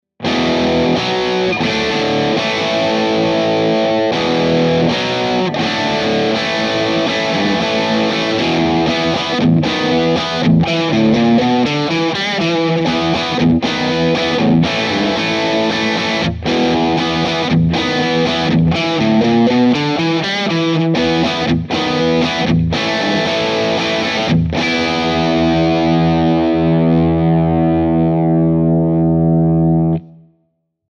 p-a-c12k-2_rock-chords.mp3